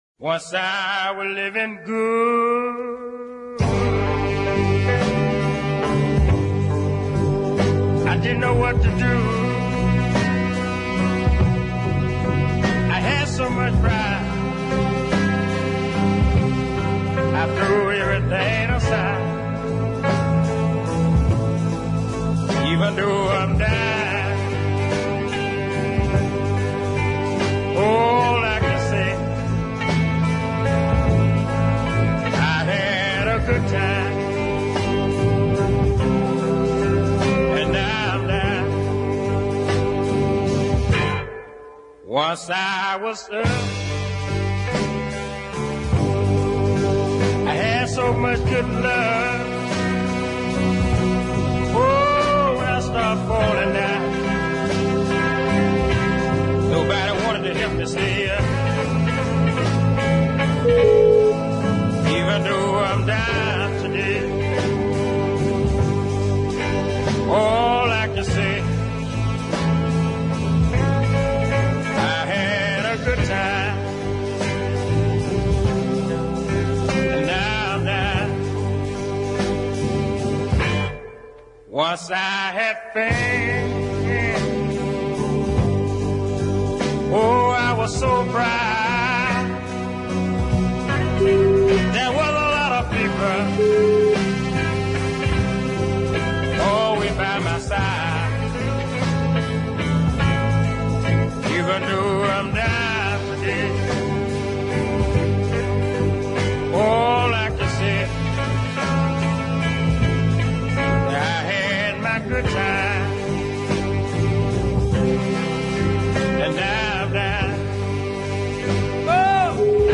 DEEP SOUL : TOWARDS A DEFINITION
truly dark, brooding, ominous piece of deep-soul